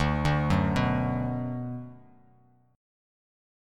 D6 Chord
Listen to D6 strummed